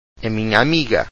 a minya ameega – lit. ‘the my friend’ – when used by a female referring to a female friend